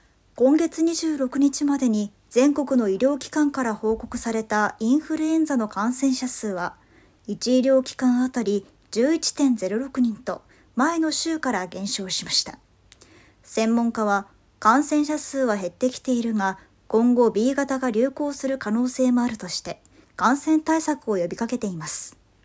ボイスサンプル
【ニュース読み】